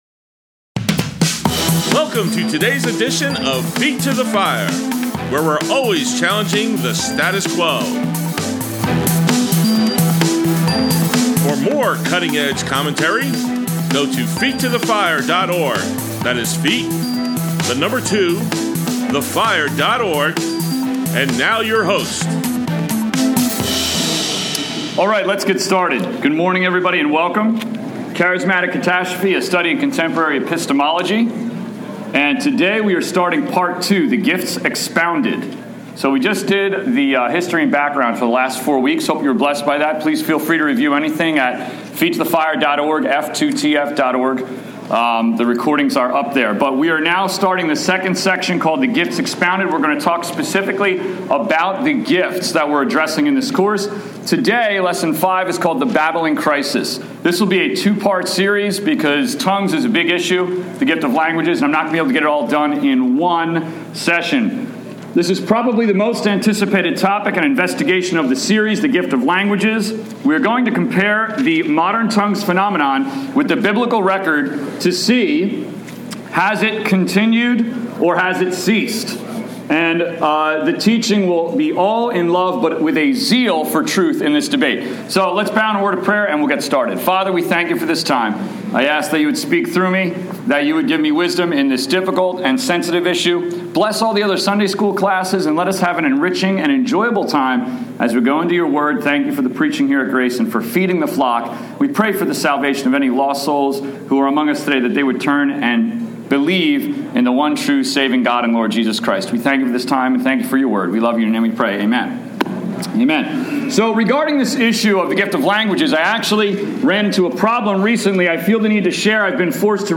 Grace Bible Church, Adult Sunday School, 2/1/18